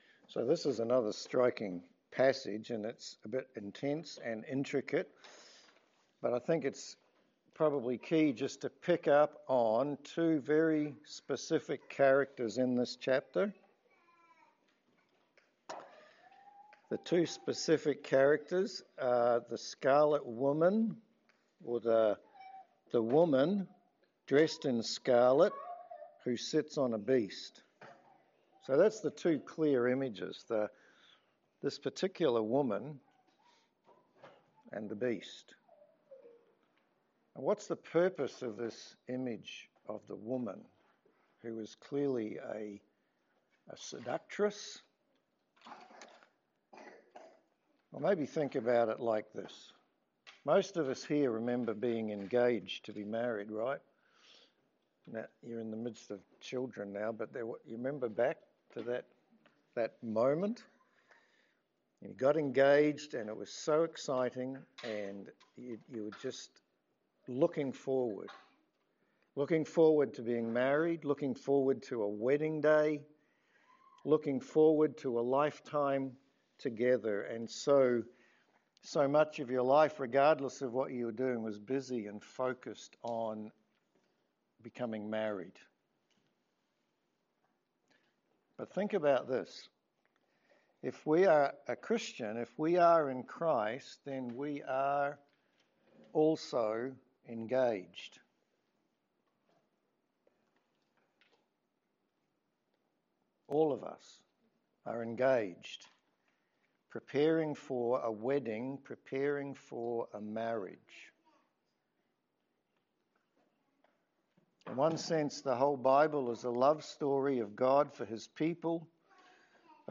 Revelation 17 Service Type: Sermon In Revelation 17 we learn more about Babylon and the Beast.